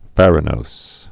(fărə-nōs)